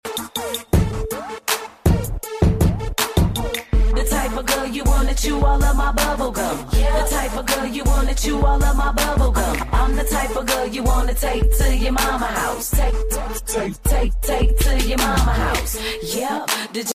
When the beat drops, Bomb sound effects free download
Bomb Mp3 Sound Effect When the beat drops, Bomb goes off - Can you keep up?